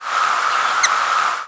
Flight call description A soft, humming "tchif", often doubled or repeated in a series.
Immature male in feeding flight with White-winged and Mourning Dove calling in the background.